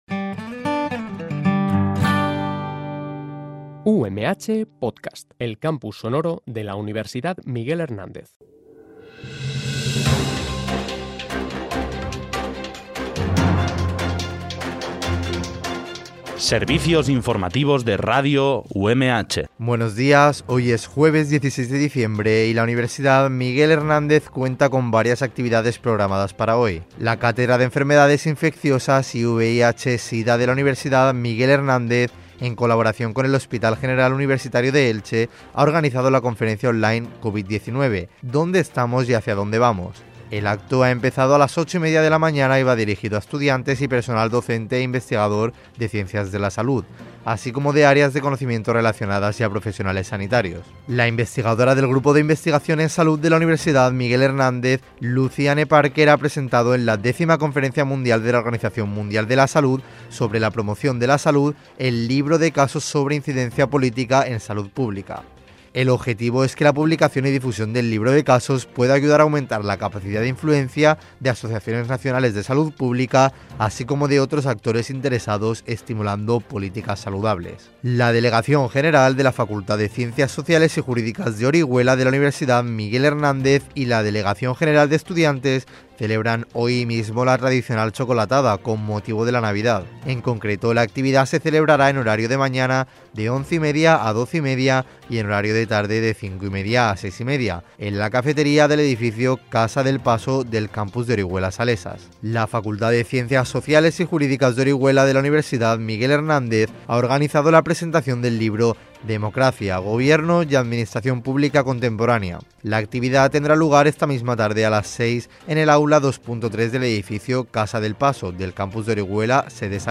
BOLETÍN INFORMATIVO UMH